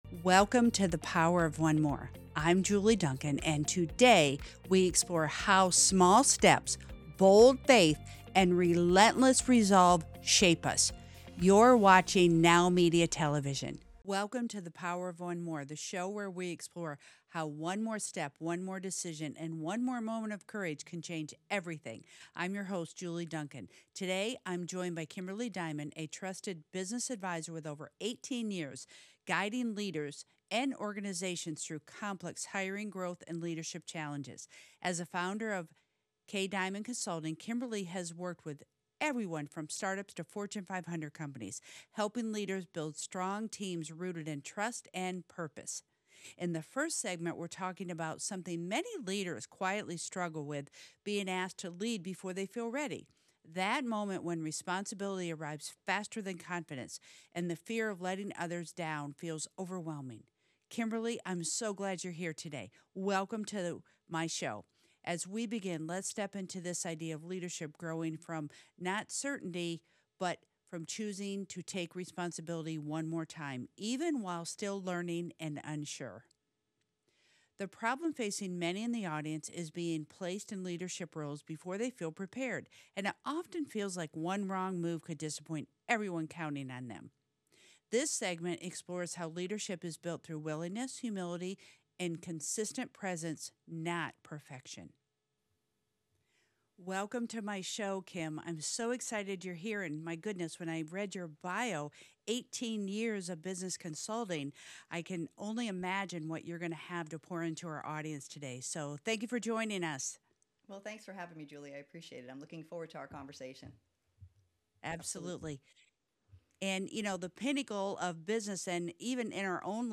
This conversation focuses on the real challenges leaders face, from overwhelming responsibility and constant change to recovering from setbacks and self-doubt.